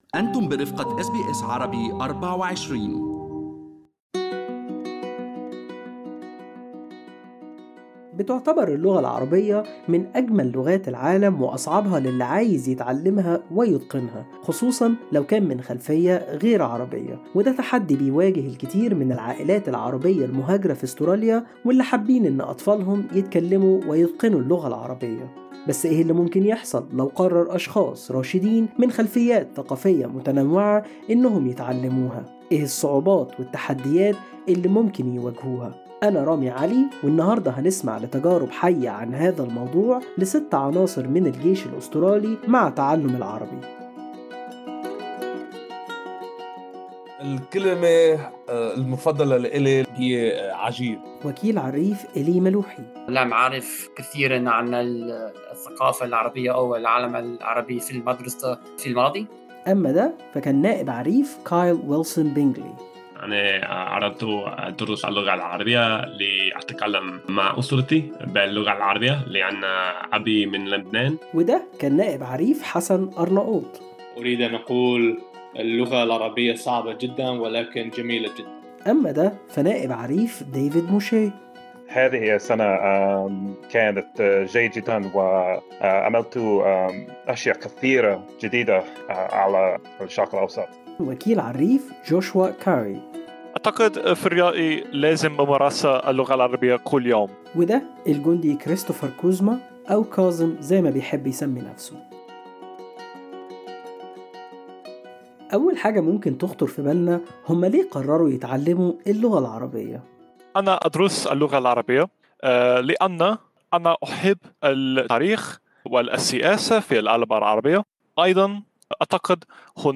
مقابلة عن طريق الفيديو مع ستة عناصر من الجيش الأسترالي ورحلة تعلمهم اللغة العربية Source: SBS Arabic24